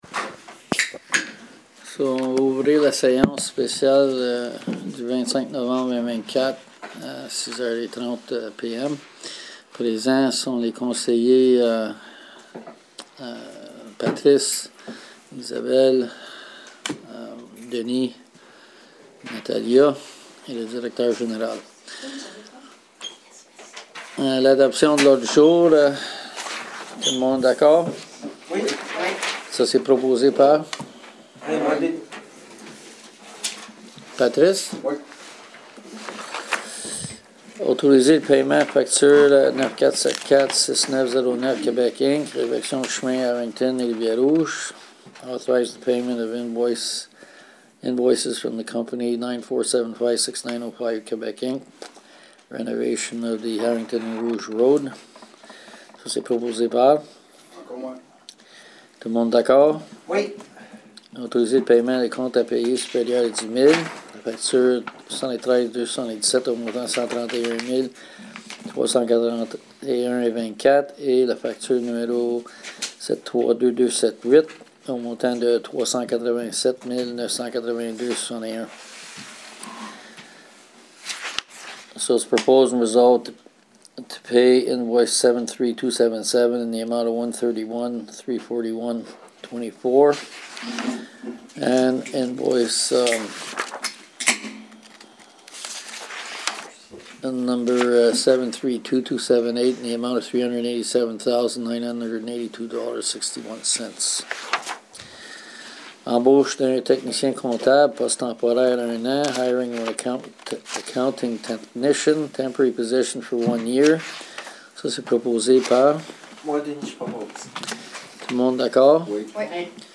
SÉANCE EXTRAORDINAIRE DU 25 NOVEMBRE 2024 / SPECIAL MEETING OF NOVEMBER 25,2024